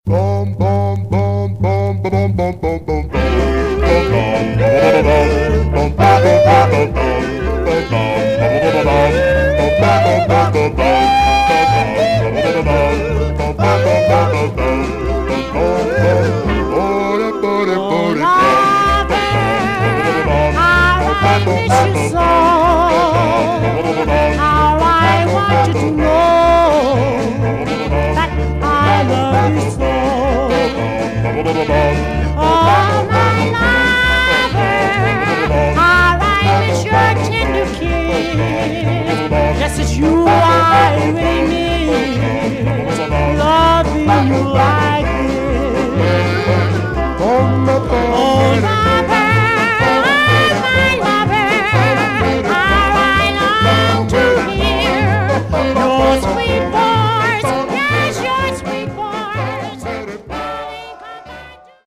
Stereo/mono Mono
Some surface noise/wear, Poor
Male Black Groups